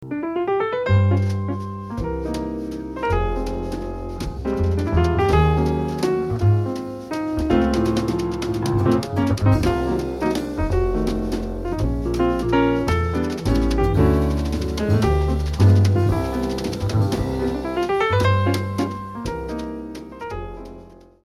Piano
Acoustic Bass
Drums, Bells
Red Gate Studio, Kent, New York
clearly-defined acoustic jazz